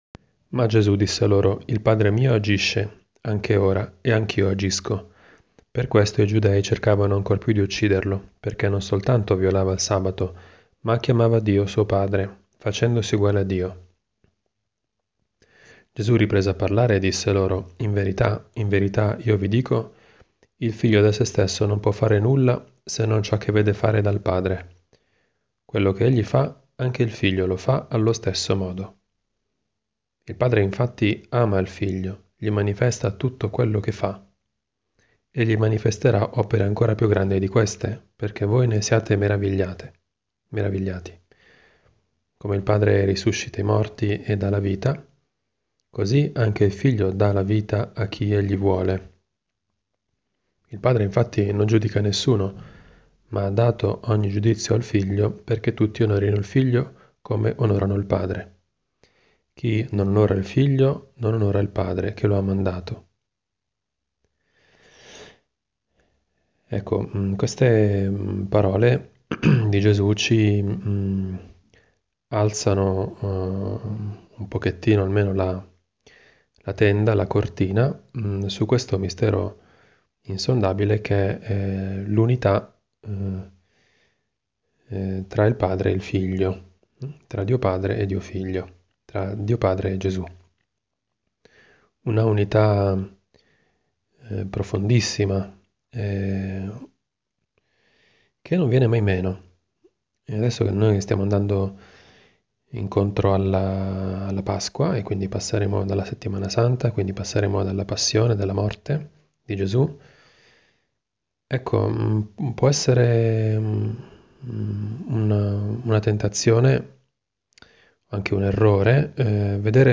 Commento al vangelo (Gv 5, 17-30) del 14 marzo 2018, mercoledì della IV settimana di Quaresima.